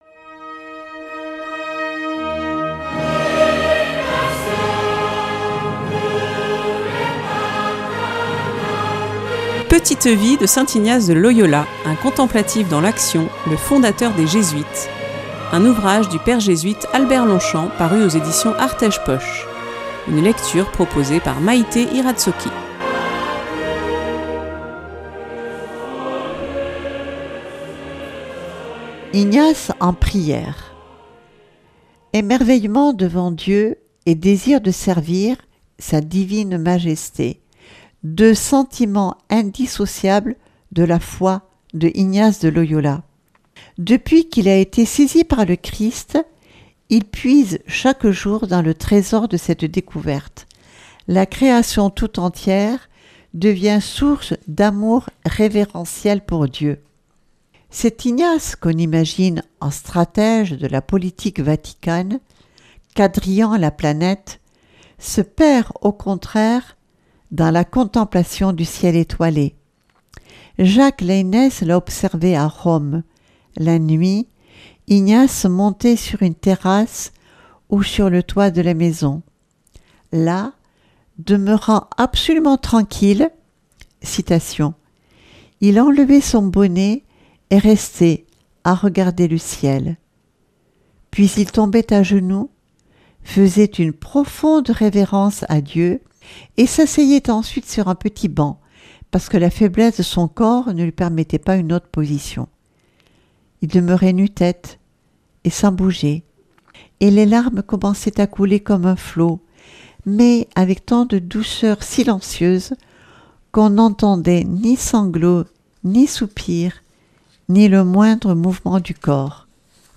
Une lecture